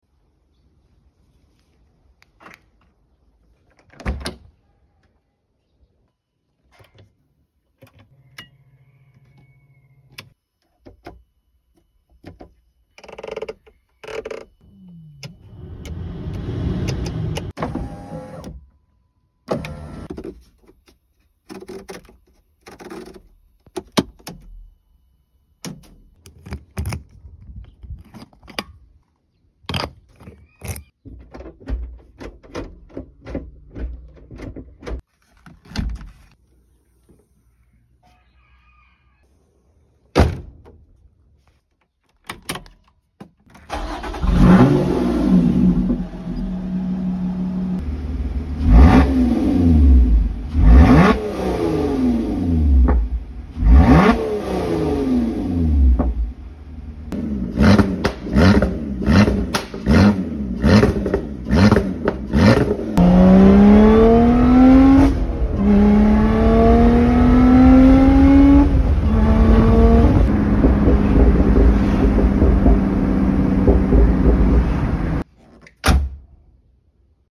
Lotus exige ASMR sound 🔛🔊 sound effects free download